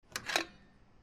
fuelpickup.mp3